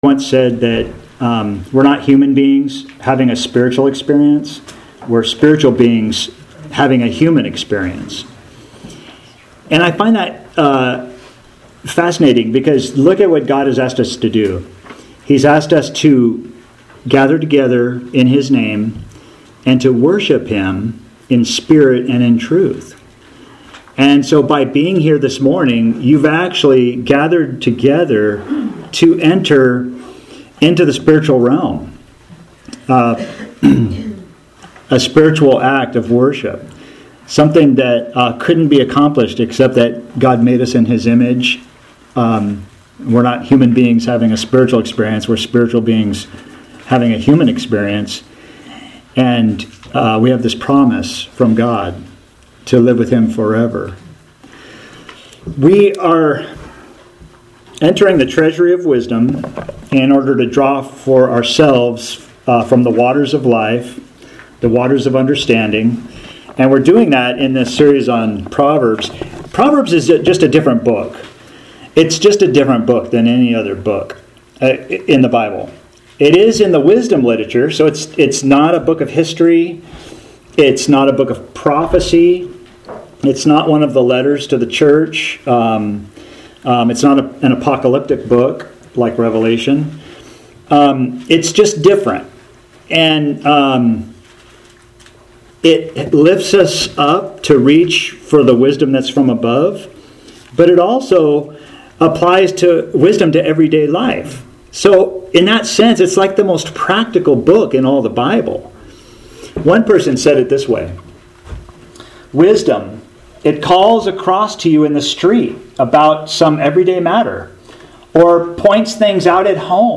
Proverbs 3 | TEXT PDF Author jstchurchofchrist Posted on March 14, 2025 March 21, 2025 Categories Sermons Tags Proverbs , Wisdom from Above Decision Point of Wisdom Decision Determines Destiny.